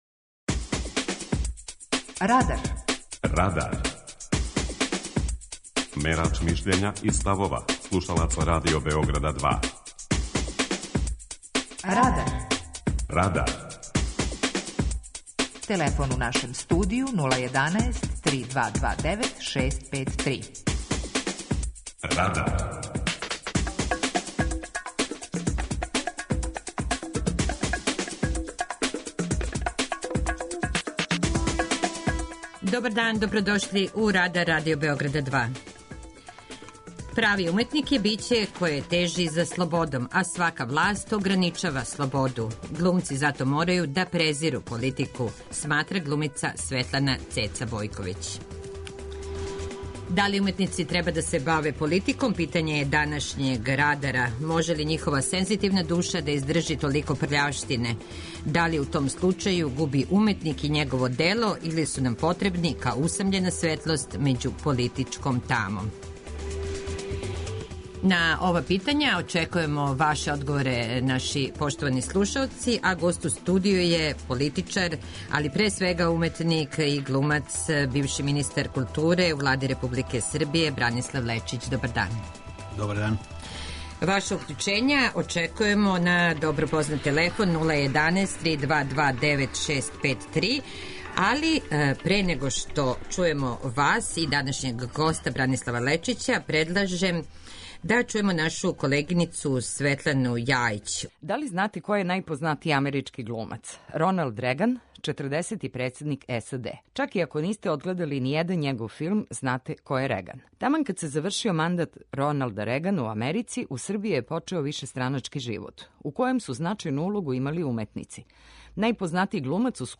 Гост: Бранислав Лечић
преузми : 18.76 MB Радар Autor: Група аутора У емисији „Радар", гости и слушаоци разговарају о актуелним темама из друштвеног и културног живота.